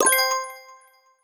Item Purchase (5).wav